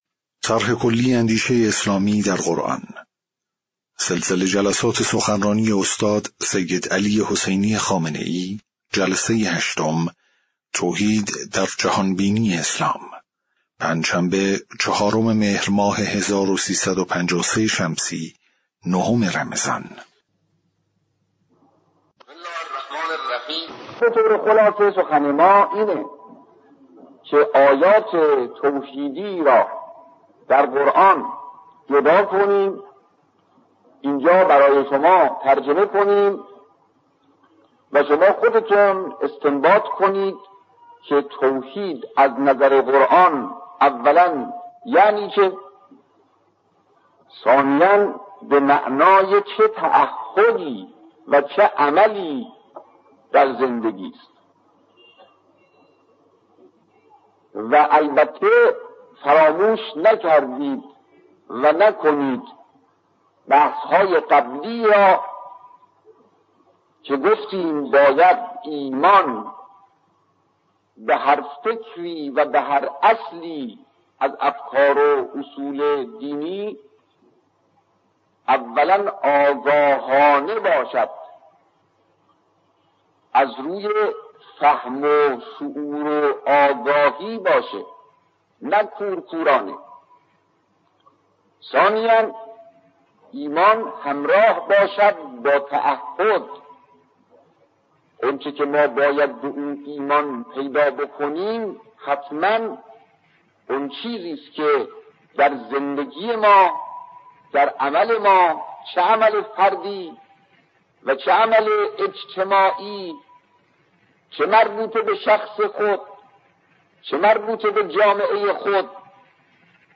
صوت/ جلسه‌ هشتم سخنرانی استاد سیدعلی‌ خامنه‌ای رمضان۱۳۵۳